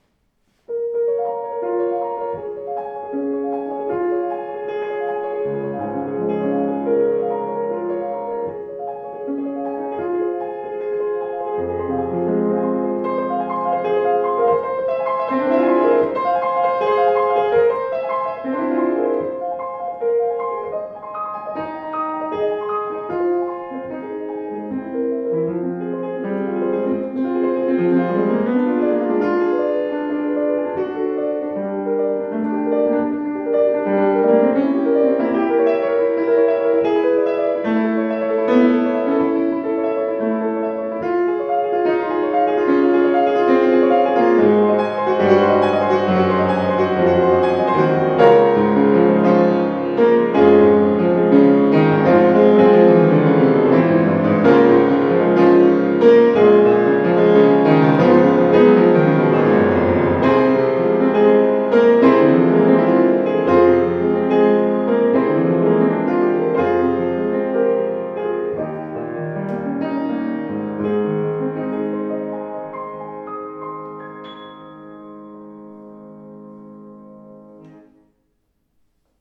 strahlender, gestaltungsfähiger Klang
(beachtenswert: das enorme Dynamikspektrum von ppp bis fff)
Flügel